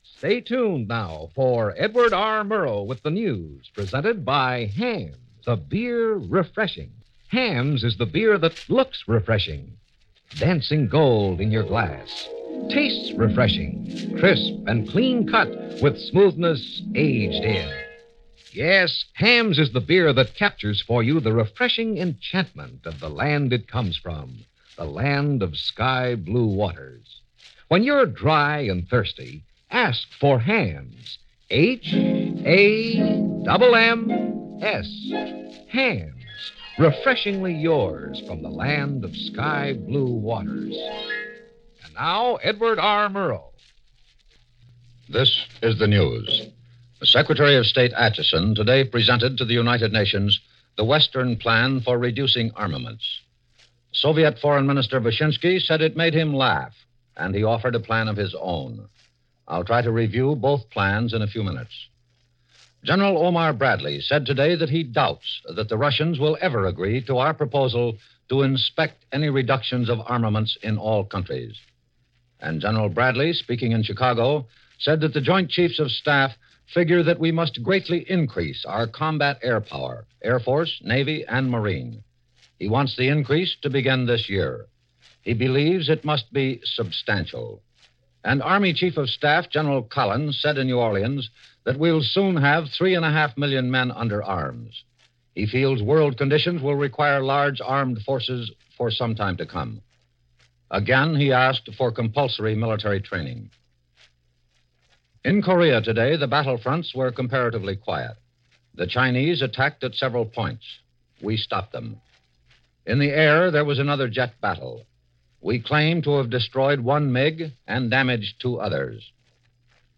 November 8, 1951 - Dean Acheson proposes - Andrei Vishinsky Laughs -UN Arms Limitation Talks - A Quiet Day In Korea - Edward R. Murrow And The News.